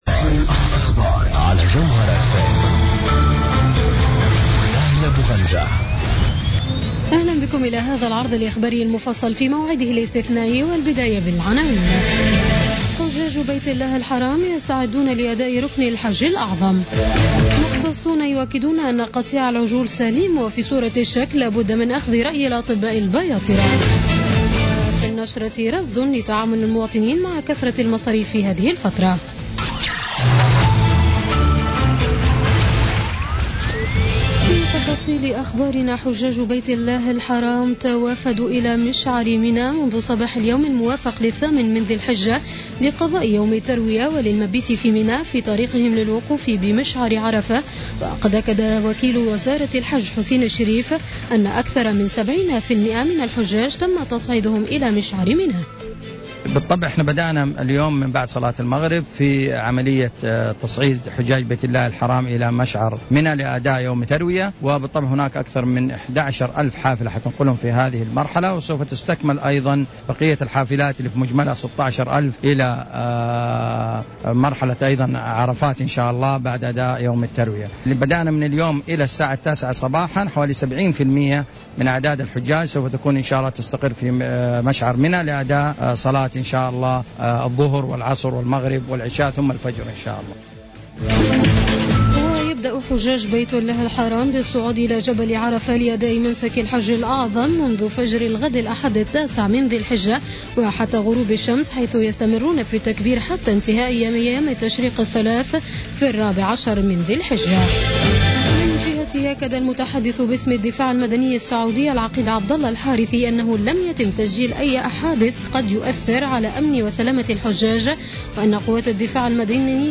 Journal Info 19h00 du samedi 10 Septembre 2016